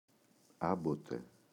άμποτε [Ꞌambote]